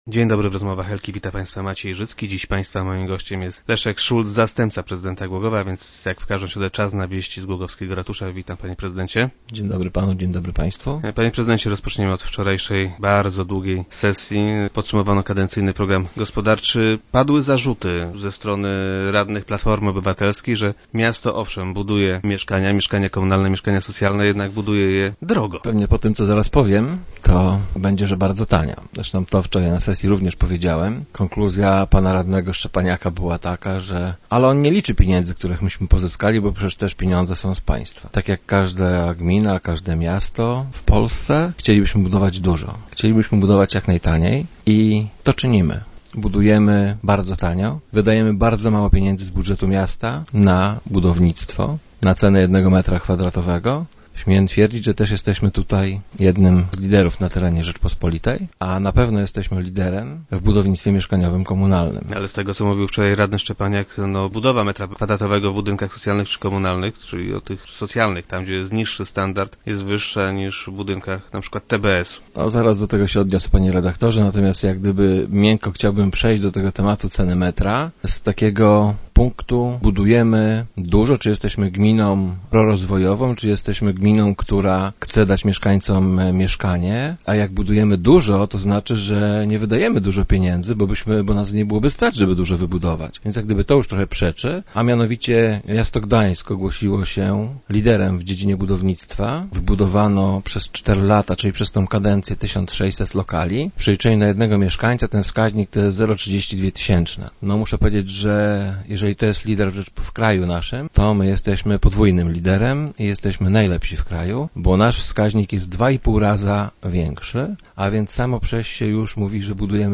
Zarzuty te odpiera Leszek Szulc, zastępca prezydenta Głogowa, który był dziś gościem Rozmów Elki.